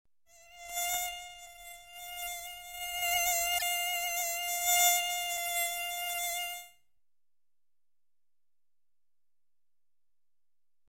Звуки комара
Раздражающий гул одинокого комара в темноте